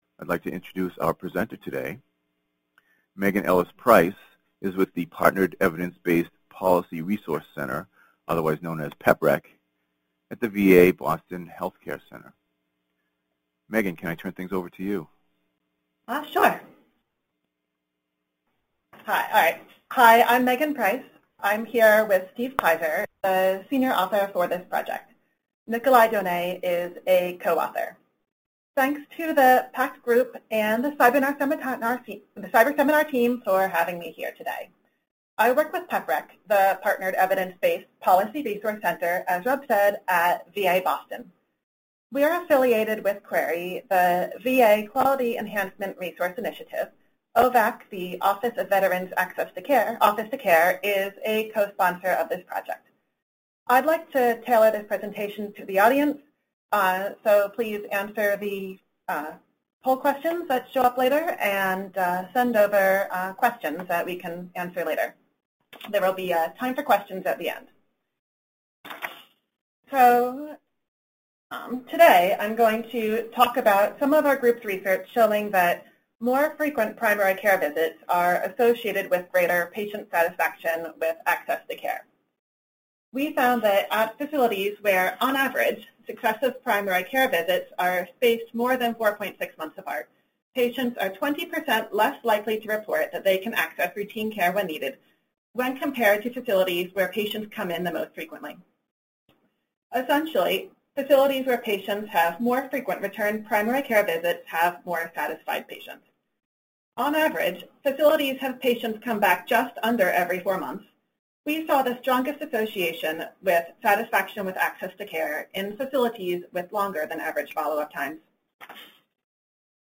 MS Seminar date